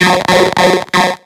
Cri de Krabby dans Pokémon X et Y.